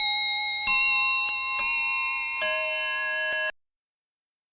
notification.ogg